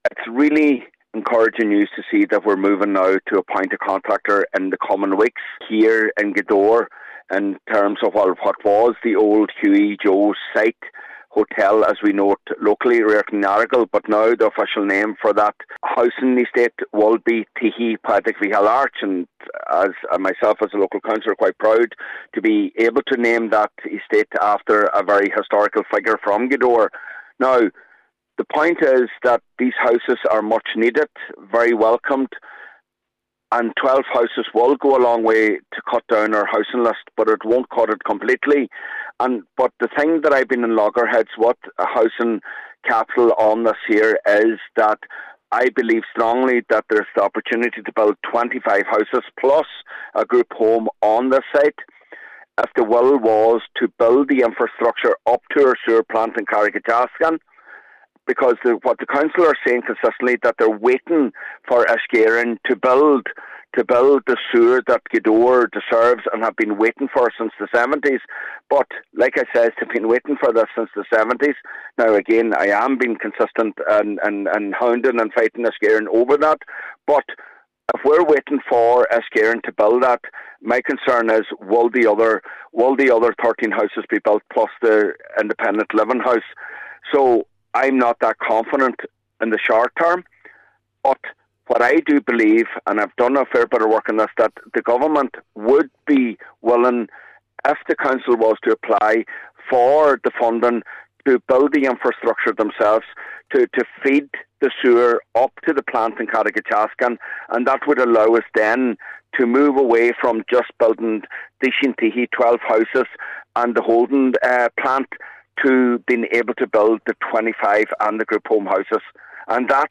Cllr Michael Cholm MacGiolla Easbuig says this is welcome, but he believes more can be done on the site………